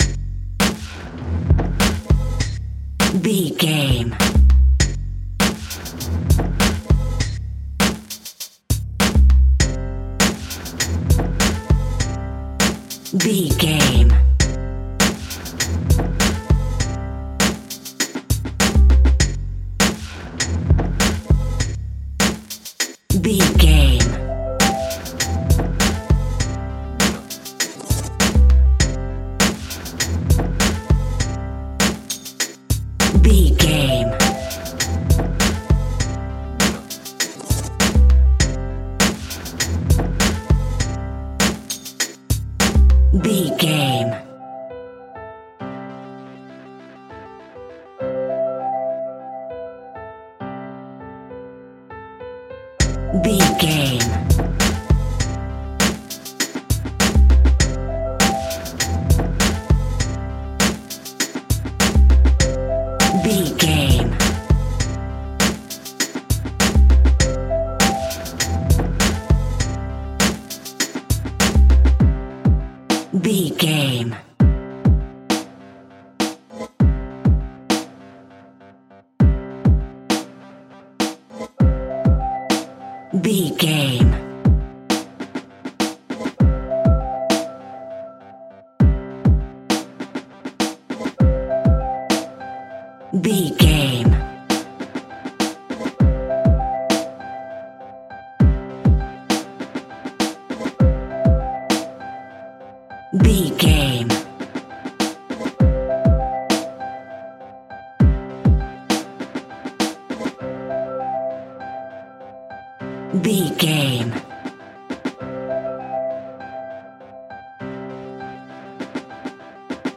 Piano Electric Fusion.
Ionian/Major
uplifting
lively
futuristic
hypnotic
industrial
playful
drum machine
electronic
techno
synths
instrumentals
synth leads
synth bass